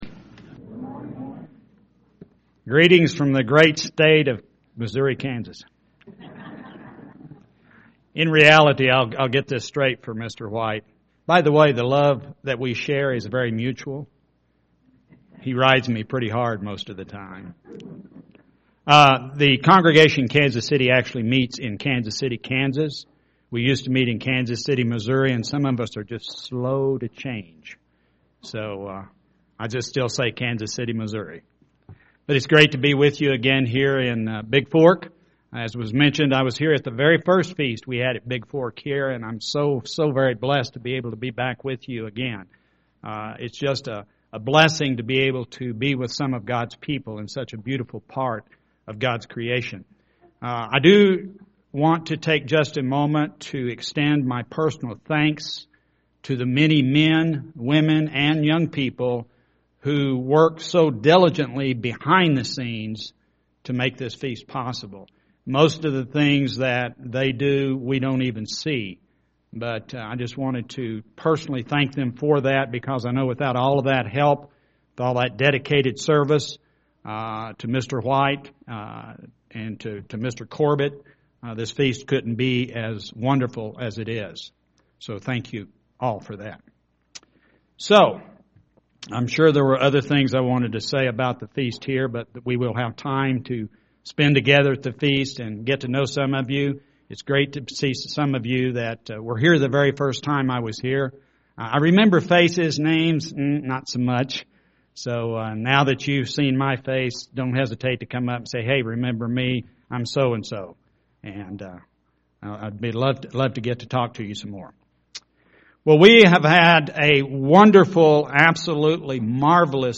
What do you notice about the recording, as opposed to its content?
This sermon was given at the Bigfork, Montana 2014 Feast site.